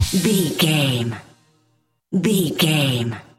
Ionian/Major
D
Fast
synthesiser
drum machine